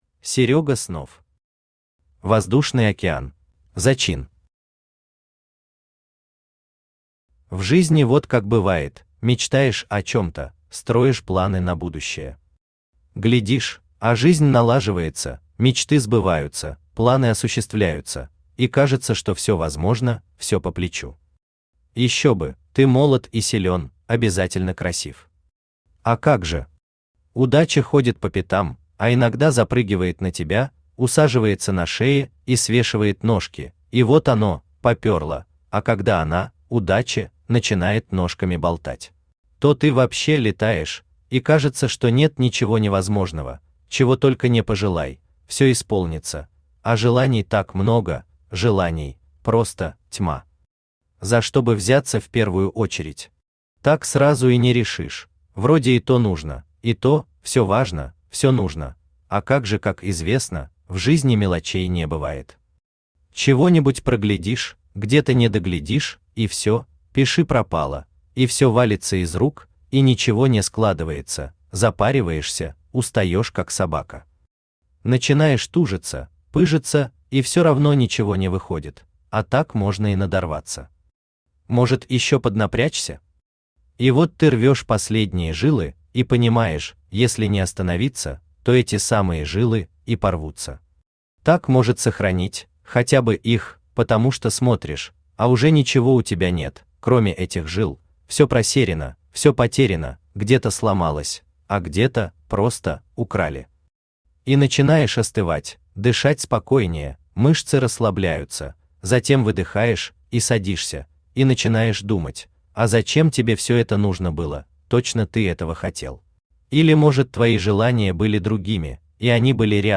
Aудиокнига Воздушный океан Автор Серёга Снов Читает аудиокнигу Авточтец ЛитРес.